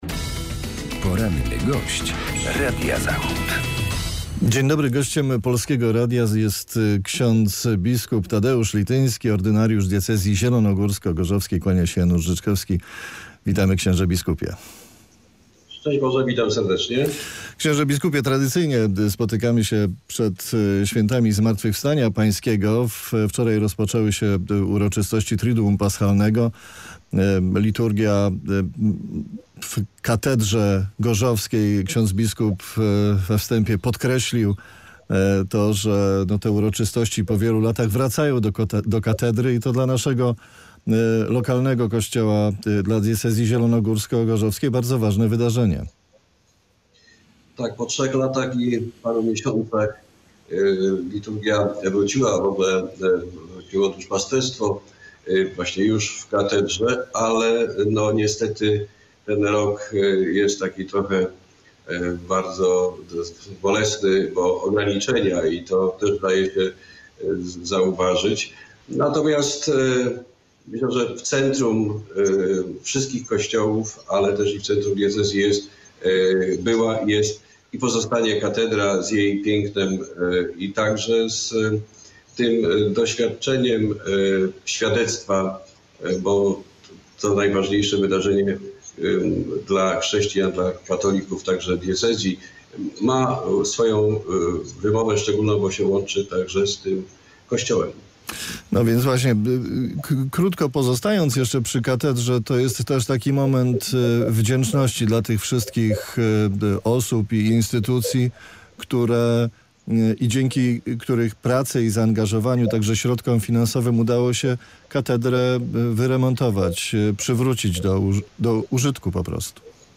Z ordynariuszem diecezji zielonogórsko-gorzowskiej rozmawia